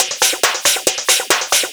DS 138-BPM B2.wav